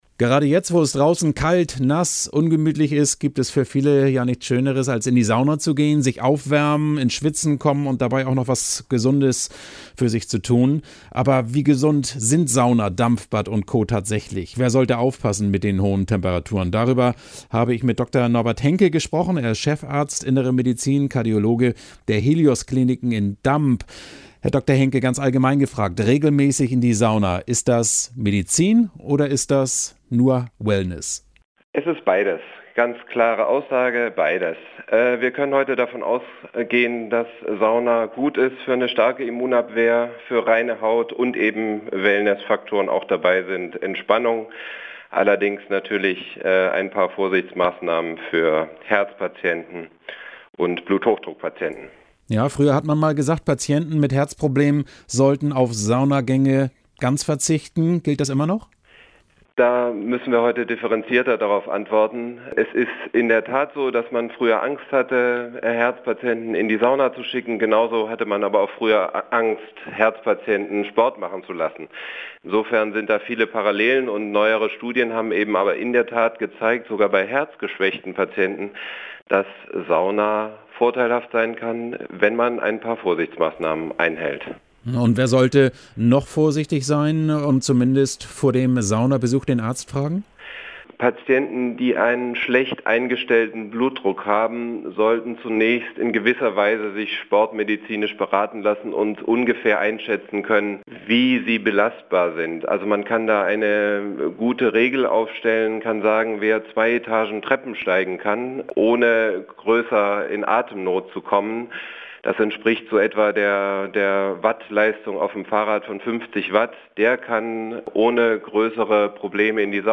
Radio-Visite